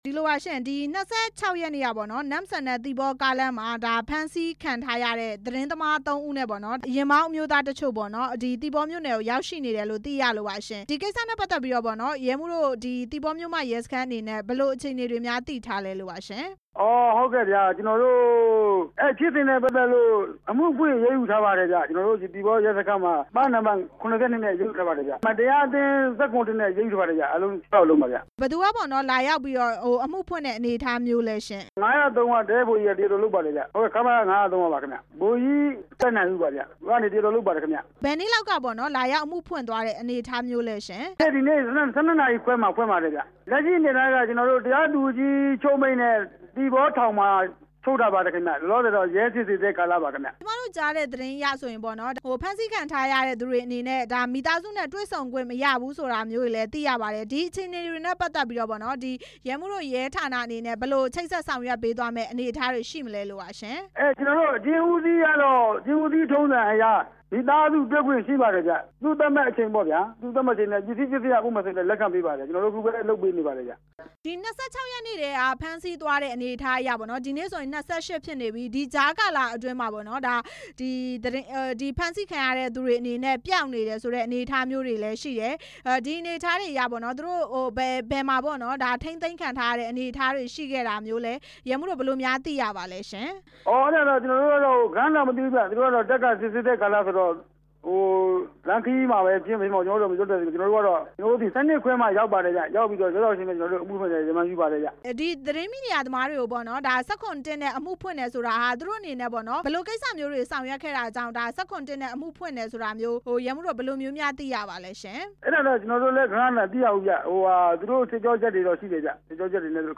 ဖမ်းဆီးခံ သတင်းထောက် အခြေအနေ မေးမြန်းချက်